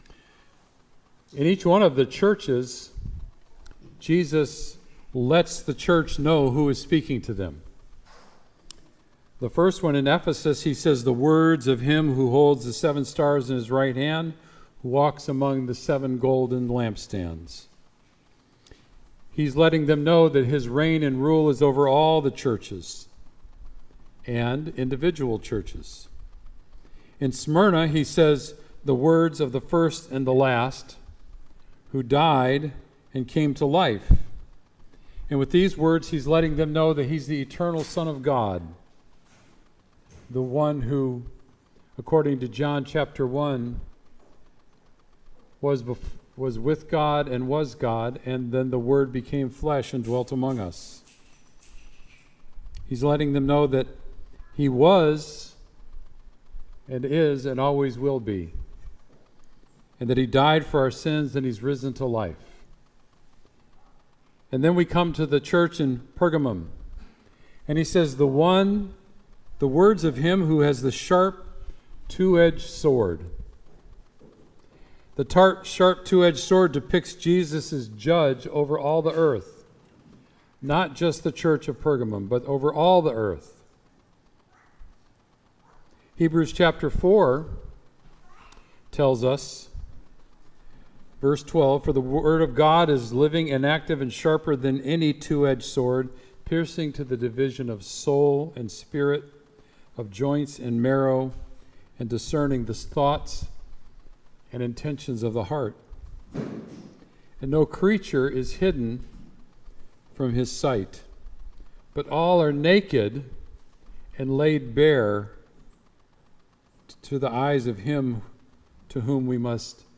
Sermon “Therefore Repent”